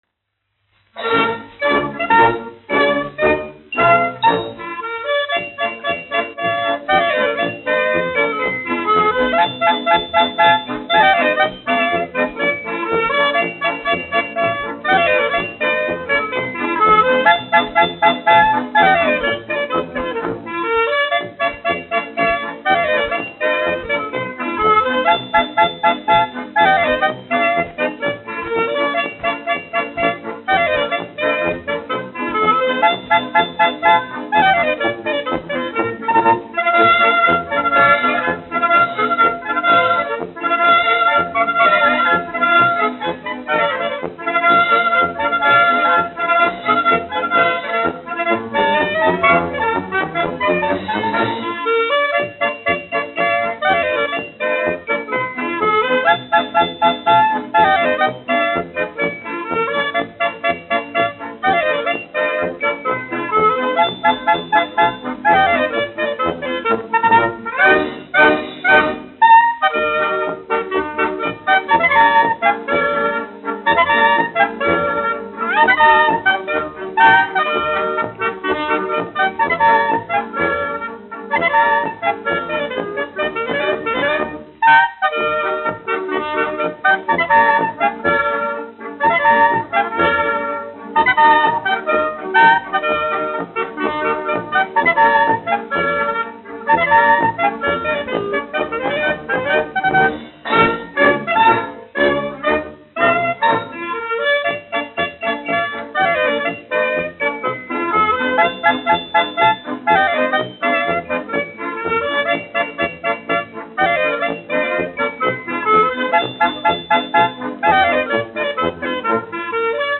1 skpl. : analogs, 78 apgr/min, mono ; 25 cm
Polkas
Latvijas vēsturiskie šellaka skaņuplašu ieraksti (Kolekcija)